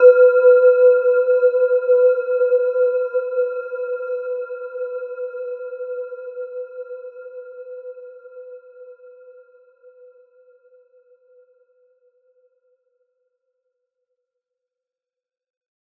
Gentle-Metallic-4-B4-f.wav